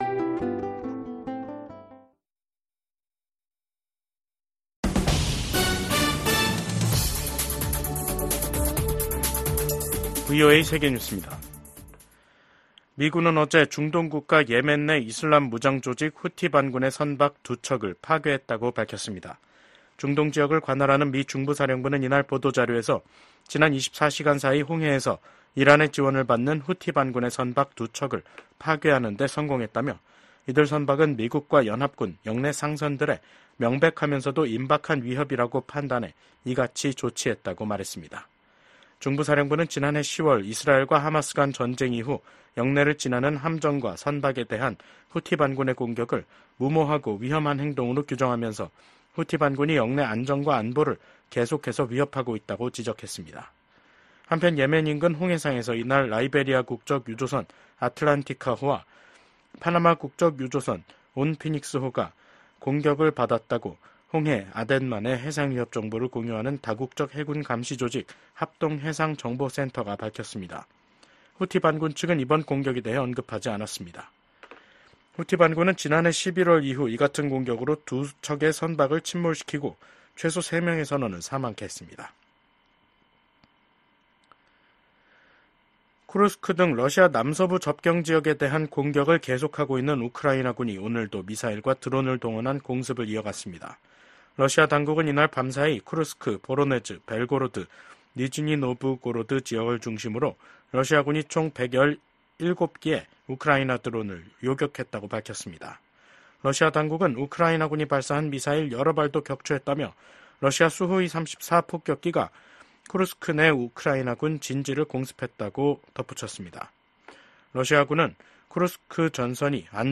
VOA 한국어 간판 뉴스 프로그램 '뉴스 투데이', 2024년 8월 14일 2부 방송입니다. 북한이 개성공단 내 철도 부속 건물 2개 동을 해체했습니다. 미 국무부는 북한이 러시아의 전쟁 수행을 지원하는 것을 좌시하지 않겠다고 밝혔습니다. 미국 국방부는 한국군의 전략사령부 창설 추진과 관련해 미한 동맹을 강조하며 긴밀하게 협력해 나갈 것이라고 밝혔습니다.